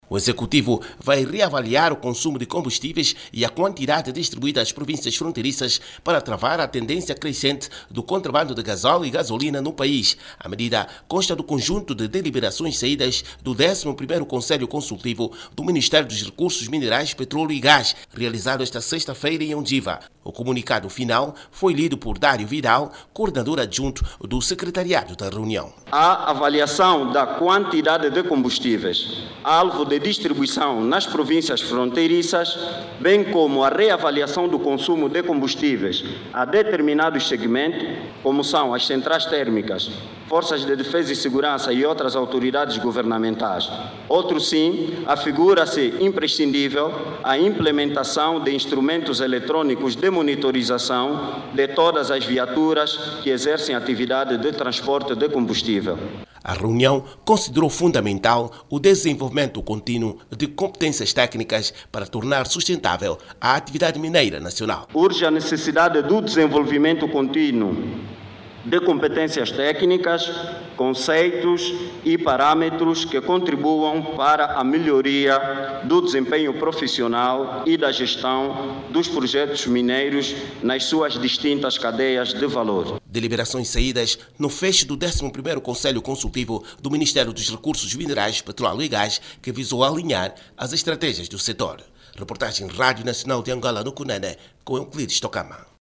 CUNENE-PETROLEO-E-GAS-07HRS.mp3